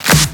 edm-clap-56.wav